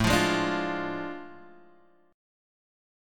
AM7sus4#5 chord